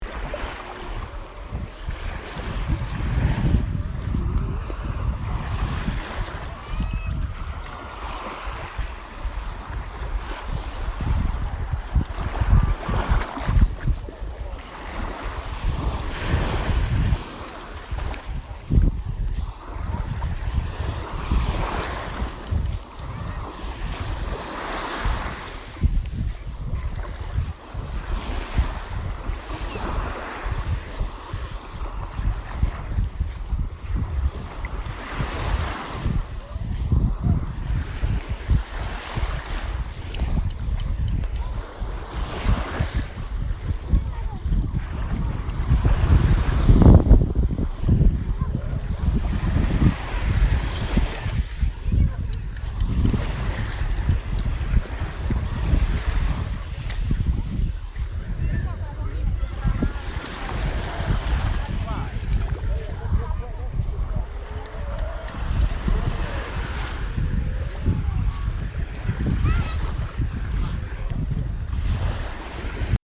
as part of the city sound guide to Chia, Sardinia, Italy.